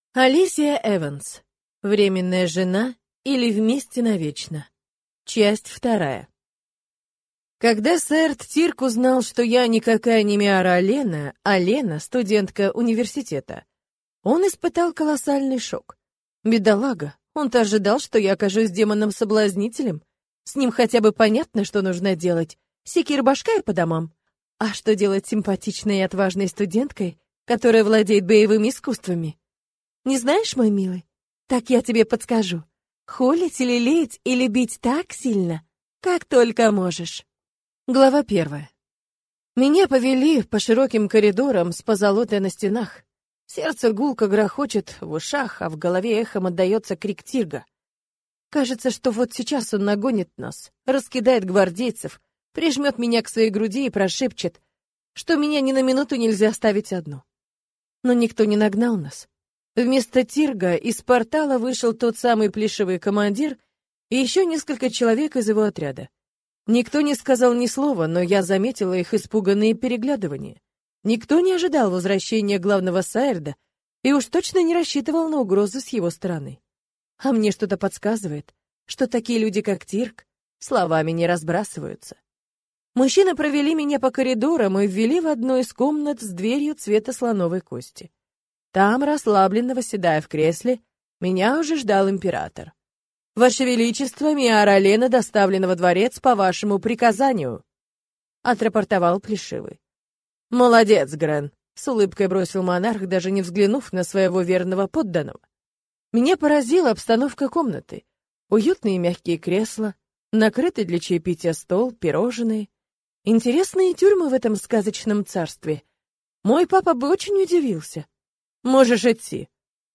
Аудиокнига Временная жена, или Вместе навечно. Часть 2 | Библиотека аудиокниг